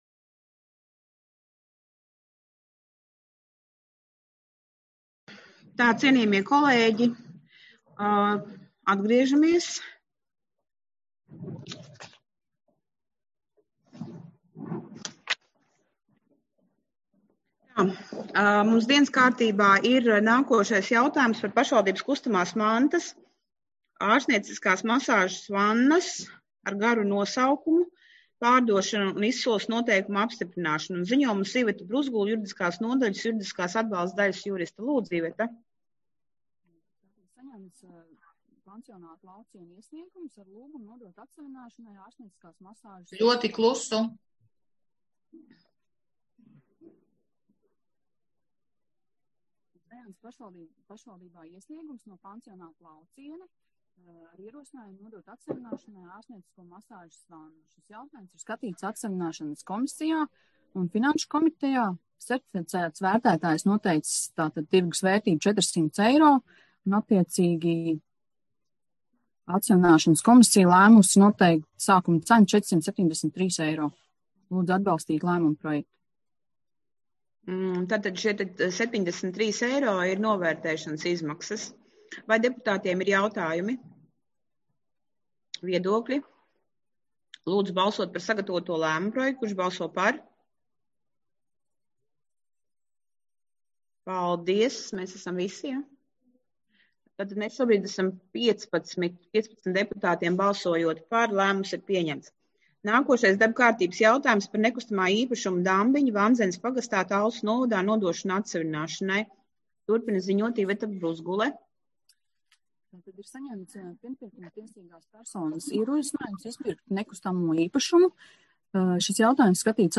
Balss ātrums Publicēts: 25.03.2021. Protokola tēma Domes sēde Protokola gads 2021 Lejupielādēt: 3.